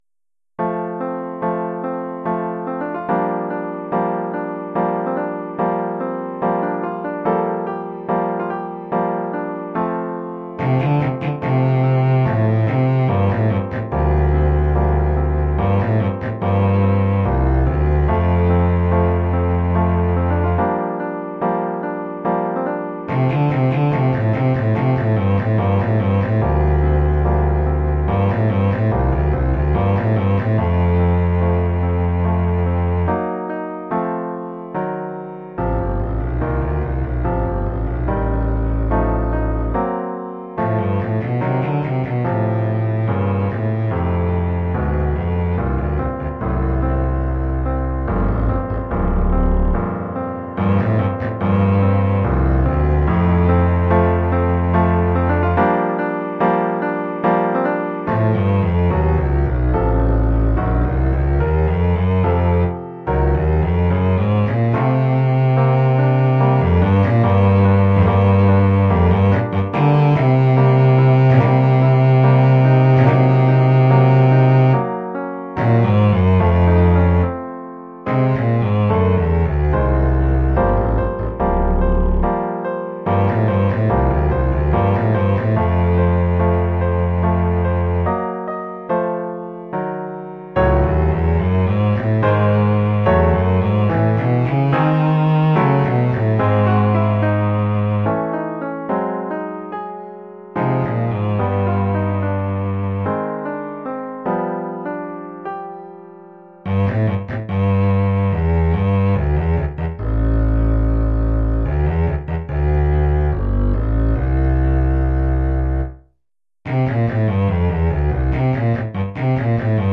Oeuvre pour contrebasse et piano.